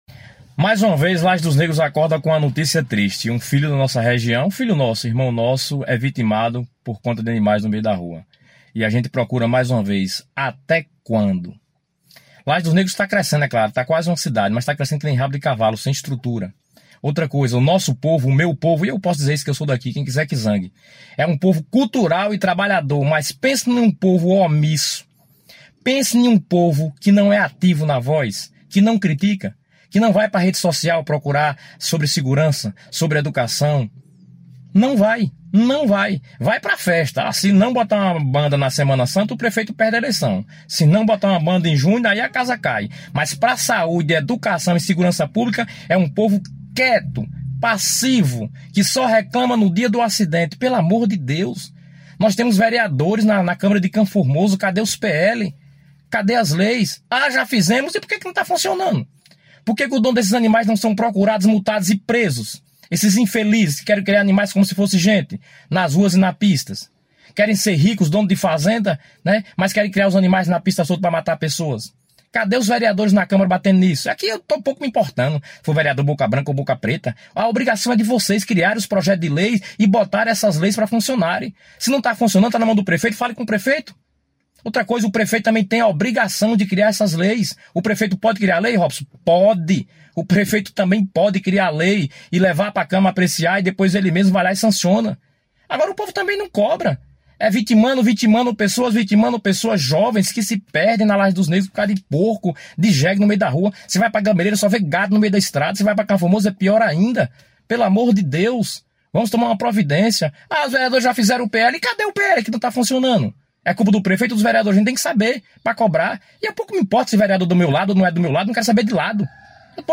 Desabafo de um morador sobre acidentes com mortes causados por animas soltos nas estradas e rodovias deixados pelos proprietários